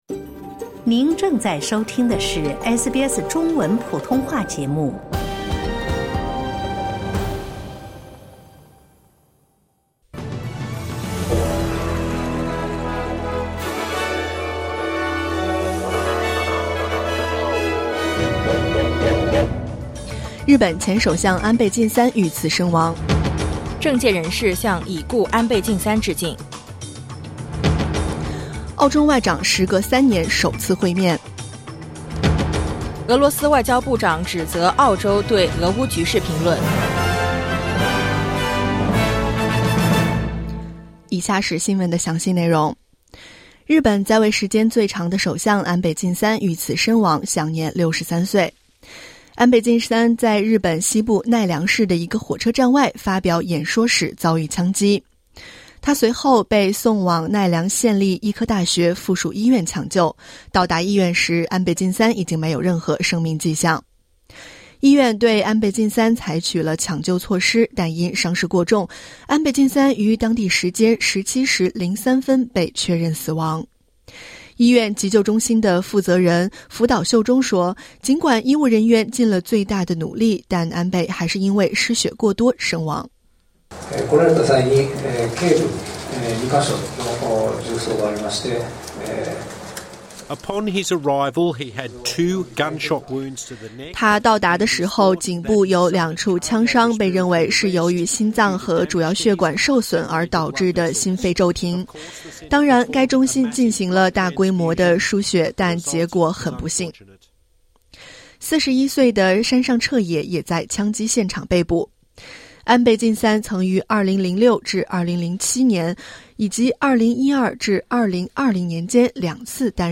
SBS早新聞(2022年7月9日）